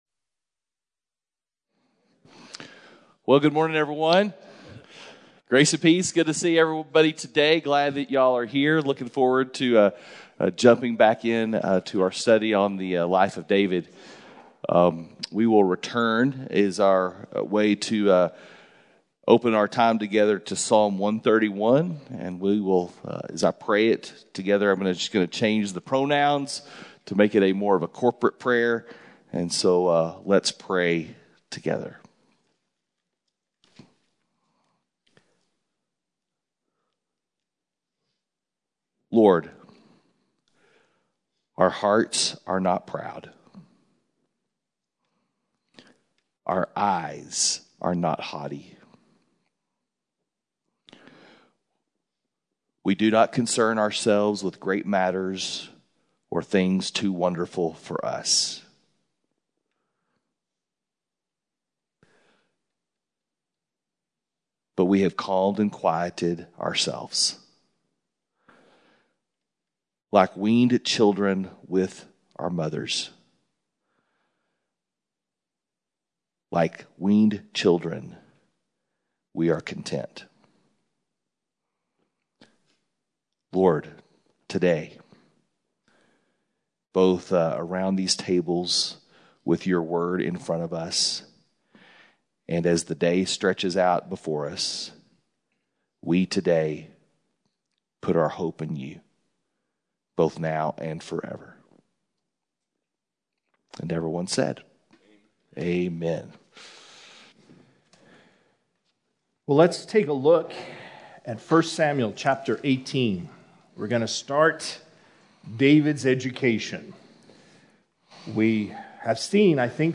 Men’s Breakfast Bible Study 3/23/21